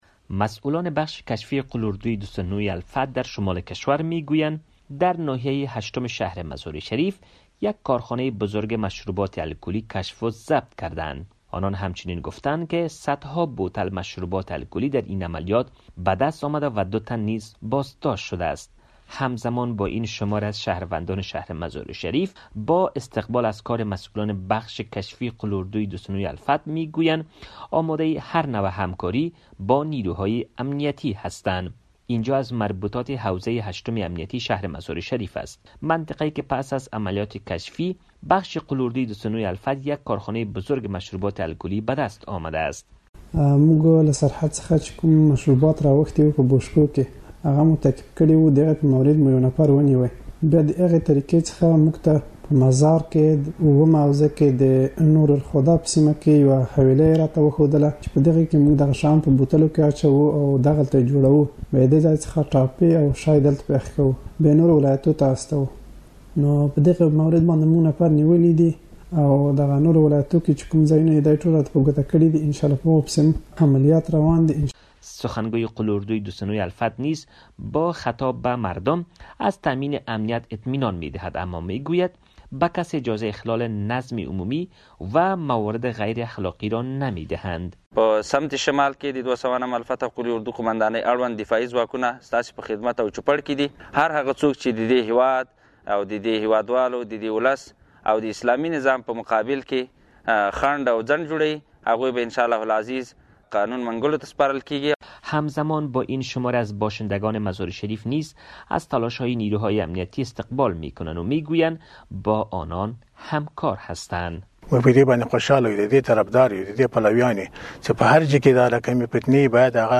کلیدواژه گزارش